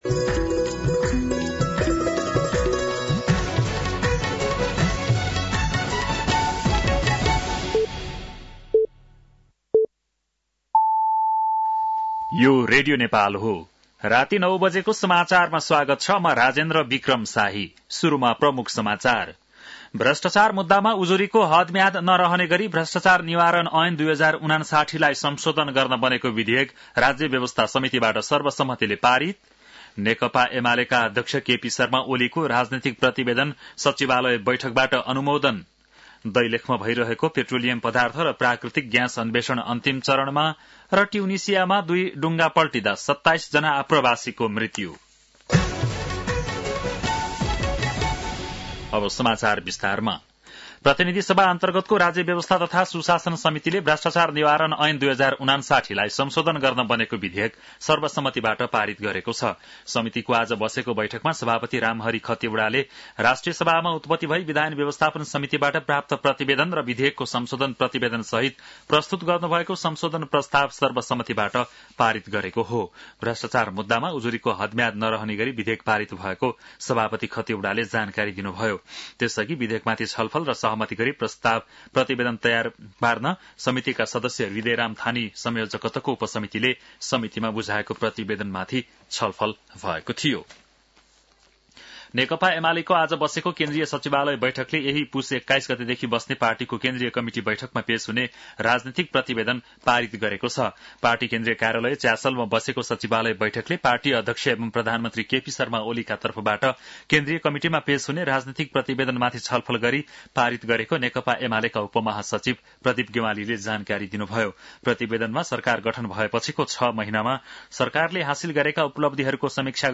बेलुकी ९ बजेको नेपाली समाचार : १९ पुष , २०८१
9-PM-Nepali-NEWS-9-18.mp3